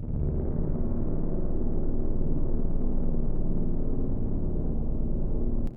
Orchestra
d1.wav